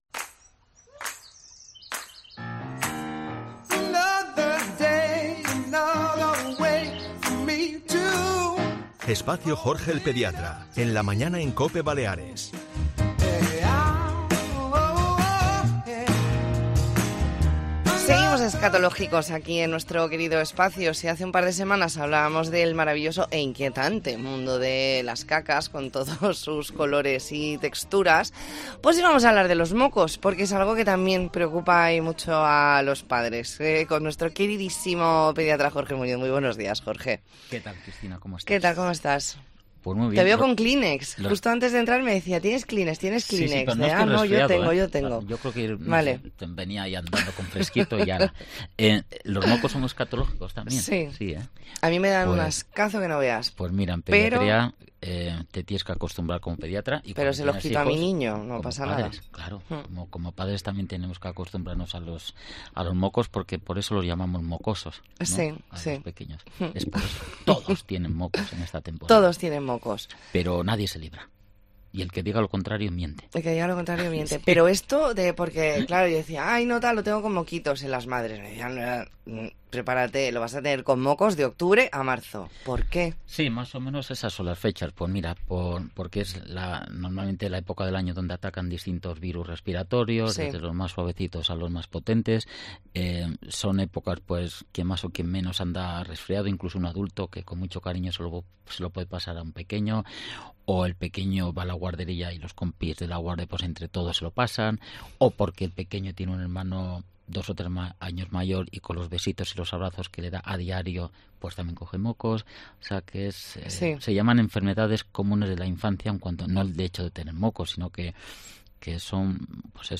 Hoy nos explica cómo limpiar los mocos a un bebé y cuándo la tos debe preocuparnos. Entrevista en La Mañana en COPE Más Mallorca, martes 5 de diciembre de 2023.